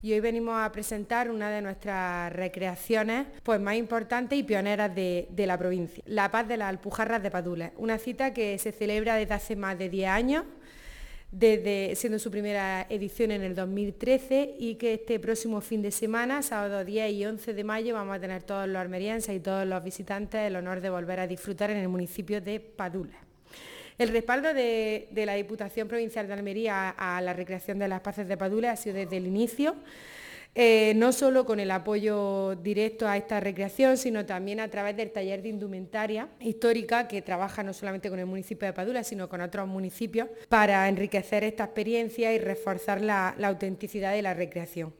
Esta mañana, la vicepresidenta y diputada de Cultura, Cine e Identidad Almeriense, Almudena Morales, ha presentado junto al alcalde de Padules, Antonio Gutiérrez, esta nueva edición.
08-05_padules_diputada.mp3